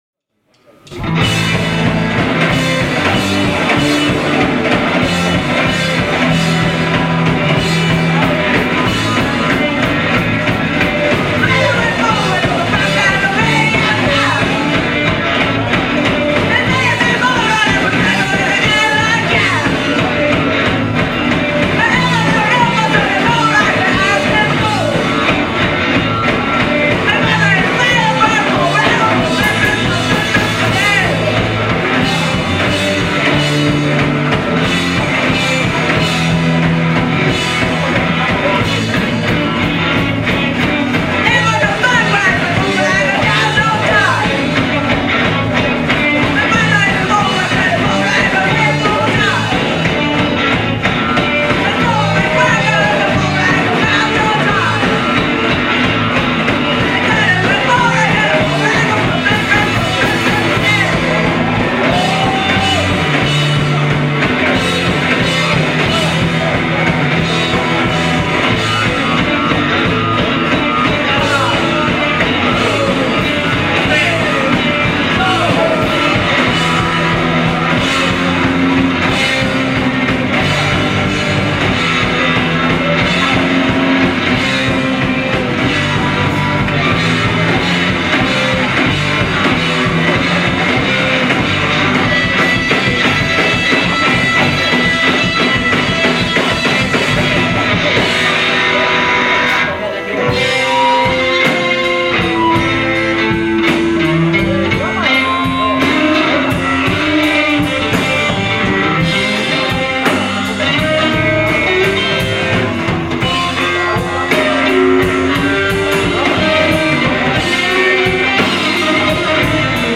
Live @ Helluva Lounge, Feb. 08 2009